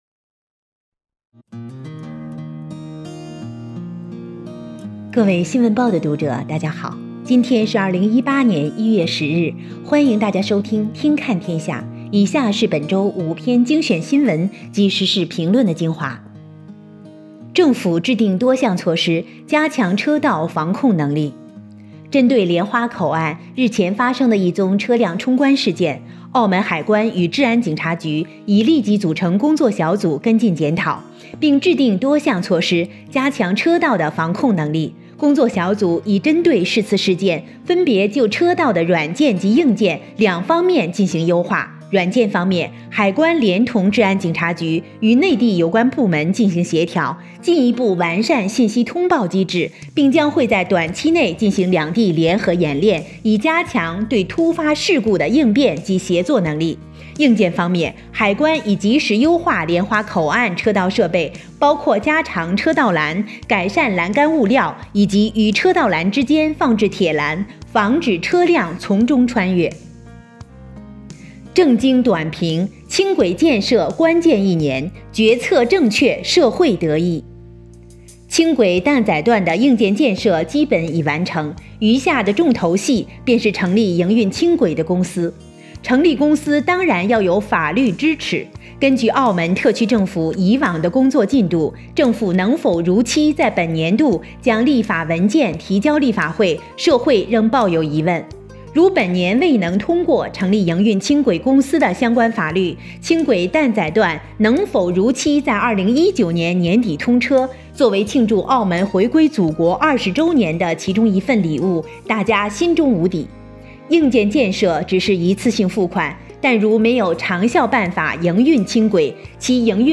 [語音播報]新聞及時事評論精華（普通話）